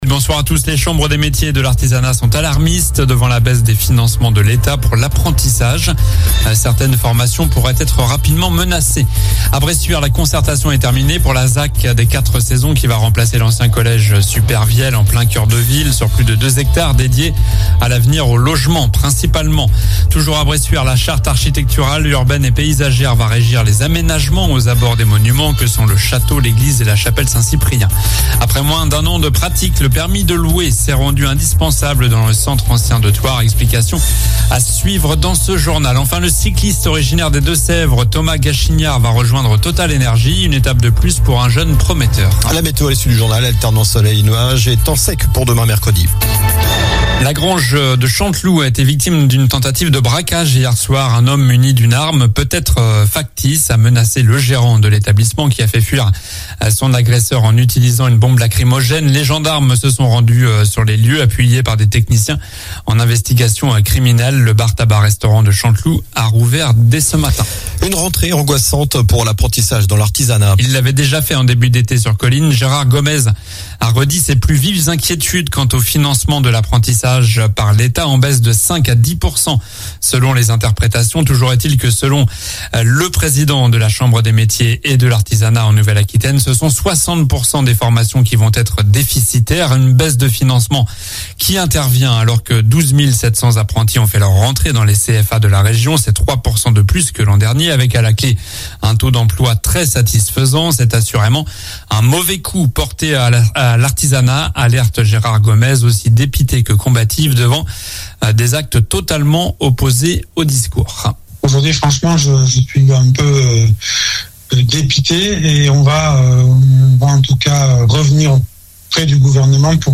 Journal du mardi 19 septembre (soir)